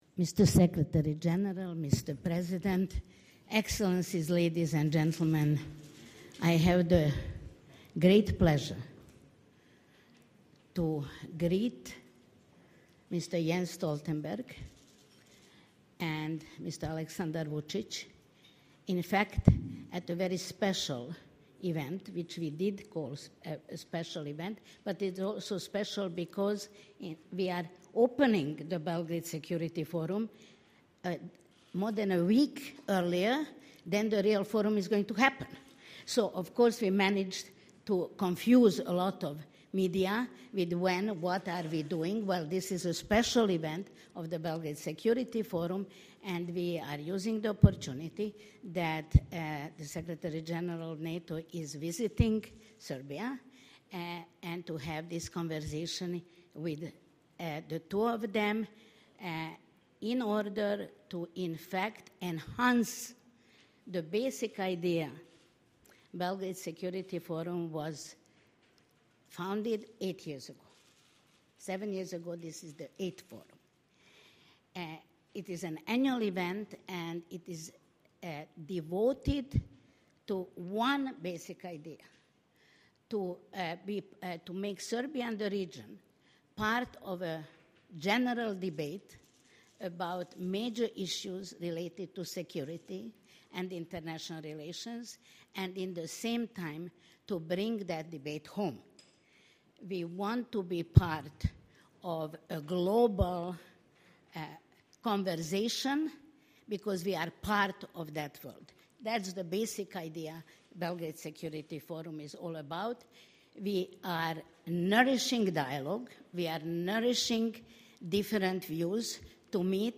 Panel Discussion
with with NATO Secretary General Jens Stoltenberg and the President of the Republic of Serbia, Aleksandar Vucić at the Belgrade Security Forum: Leadership for a Secure Region